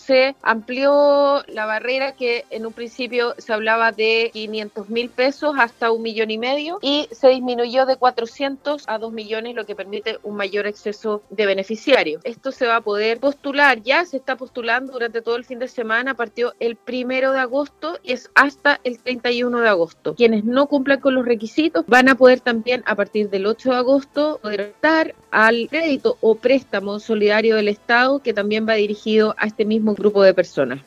En conversación con Radio Sago, la Seremi de Desarrollo Social y Familia en la región, Soraya Said explicó los beneficios que actualmente están disponibles para la población en el contexto de la crisis sanitaria y económica que vive el país.